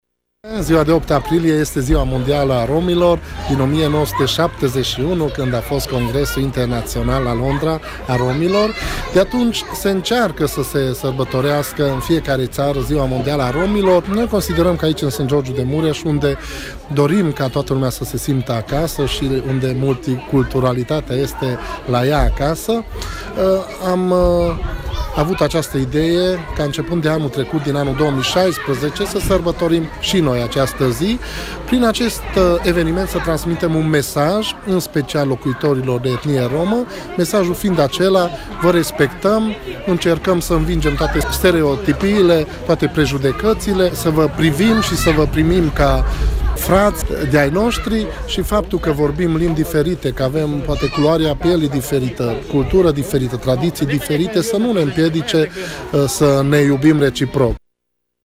Prezent la eveniment, Sófalvi Szabolcs, primarul comunei Sângeorgiu de Mureș, unde trăiesc peste o mie de romi, a spus la rândul său: